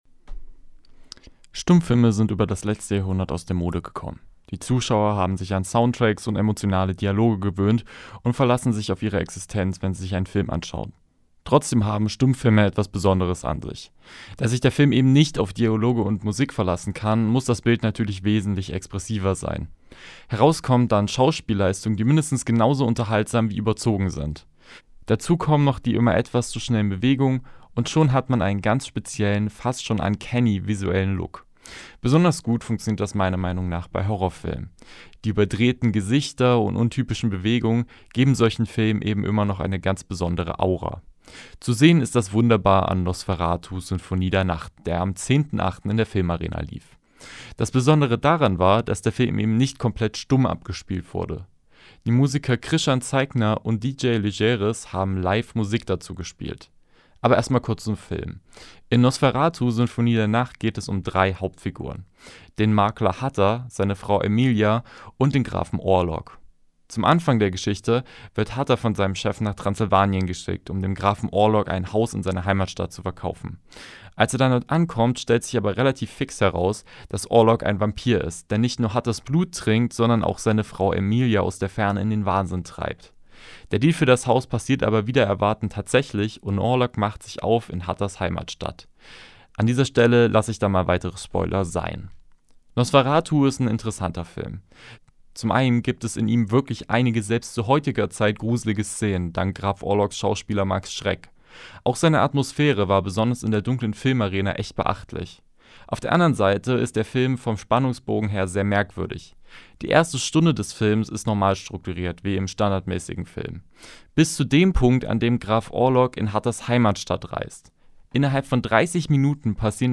Liveberichterstattung vom Theatervorplatz.